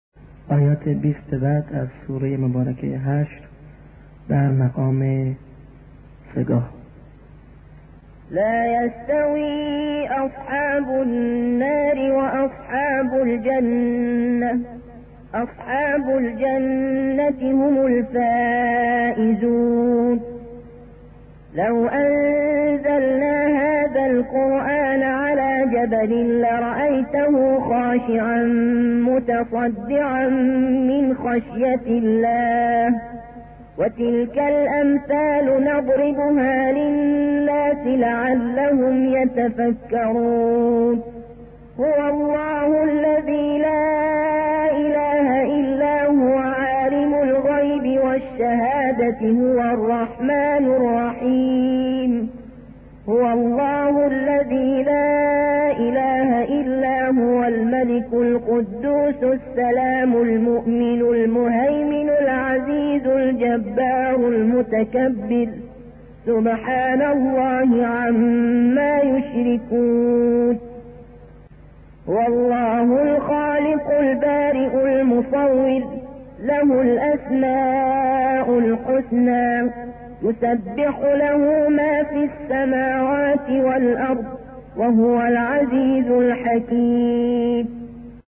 ترتیل در مقام سه گاه
segah-Tartil.mp3